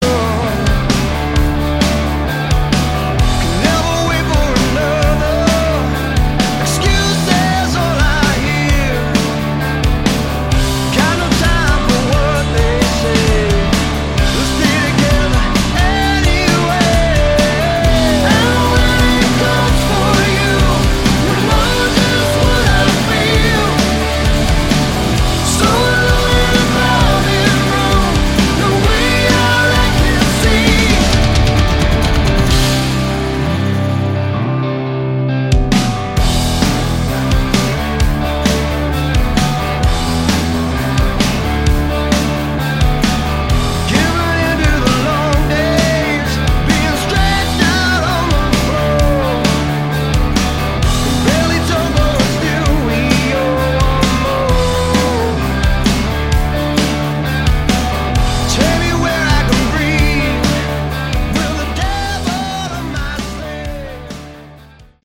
Category: Hard Rock
guitar
bass
vocals
drums